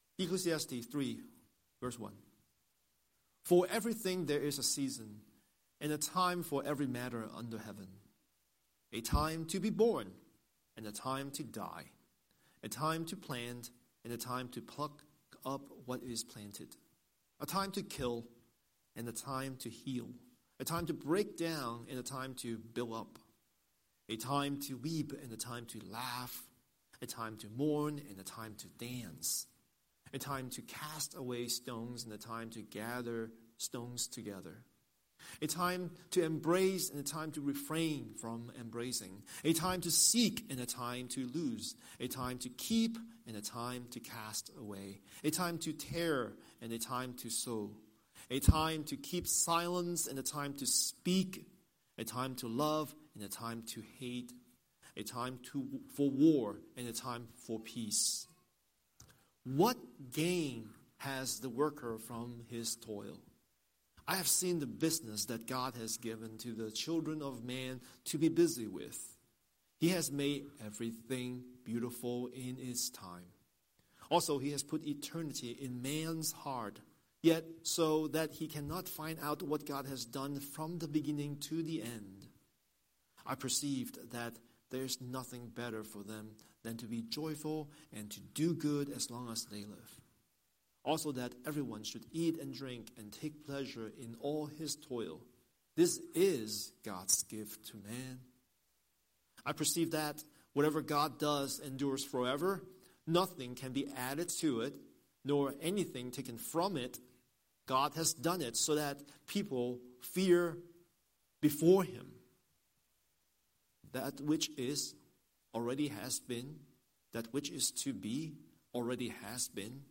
Scripture: Ecclesiastes 3:1–15 Series: Sunday Sermon